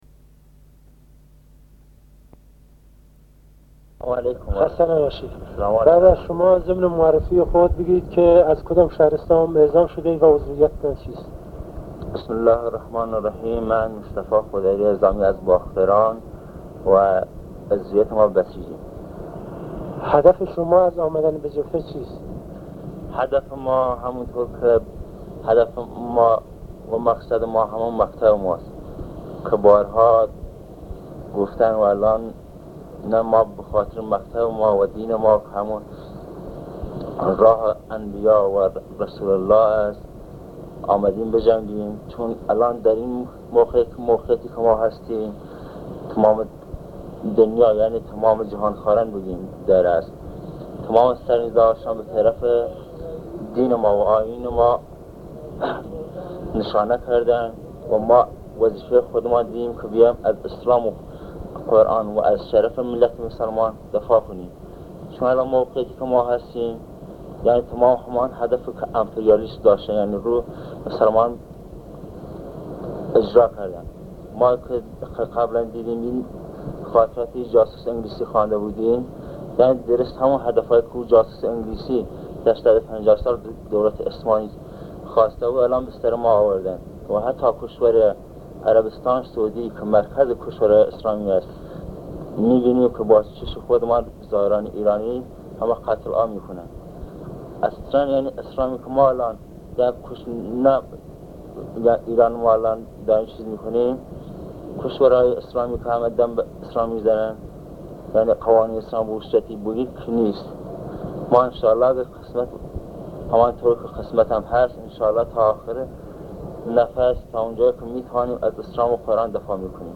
صدای ماندگار/ گفتگو